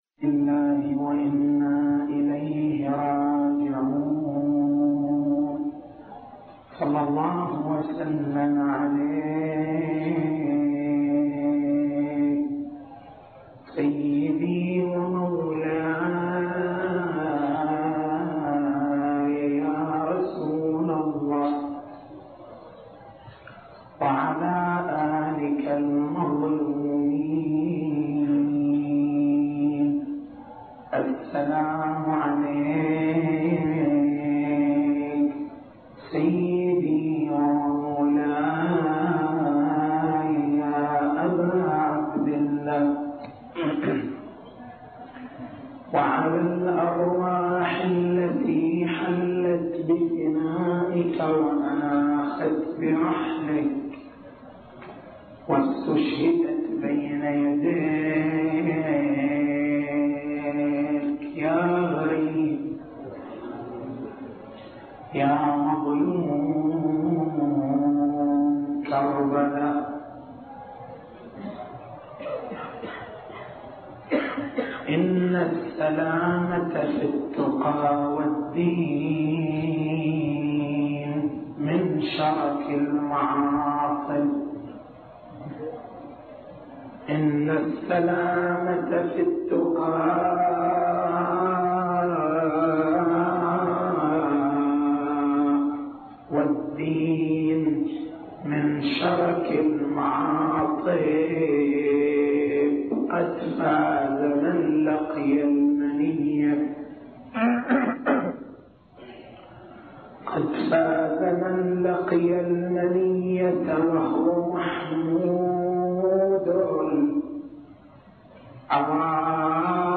تاريخ المحاضرة: 05/01/1424 نقاط البحث: مفهوم الغلو في مدرسة أهل البيت (ع) مناقشة شبهة الغلو المثارة على الشيعة التسجيل الصوتي: تحميل التسجيل الصوتي: شبكة الضياء > مكتبة المحاضرات > محرم الحرام > محرم الحرام 1424